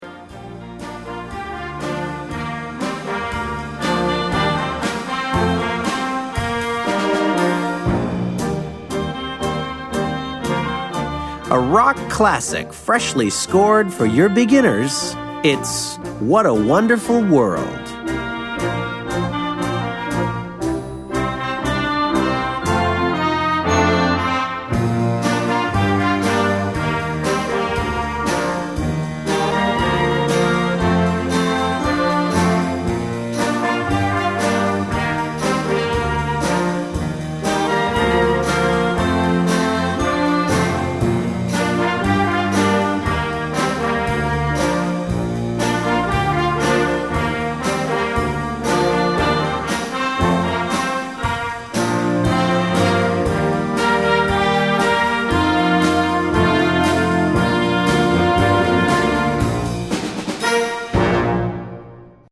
Gattung: Werk für Jugendblasorchester
Besetzung: Blasorchester